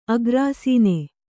♪ agrāsīne